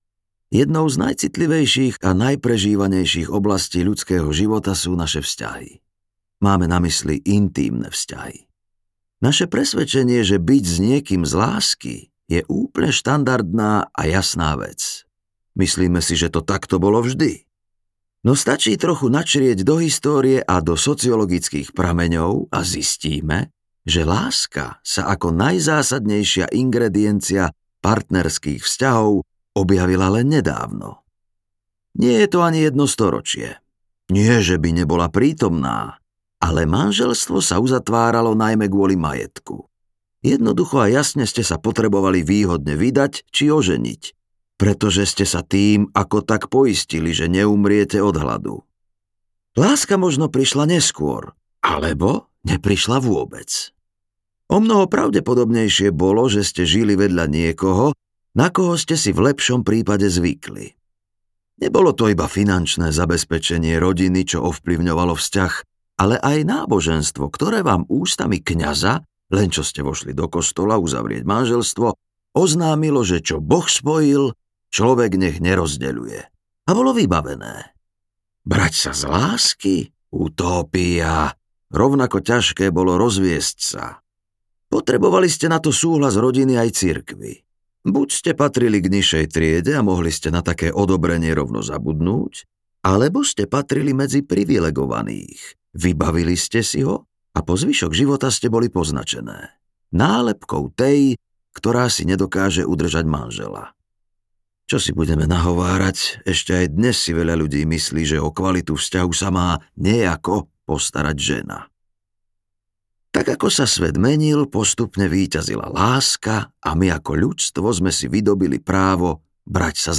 Vzťahy a mýty audiokniha
Ukázka z knihy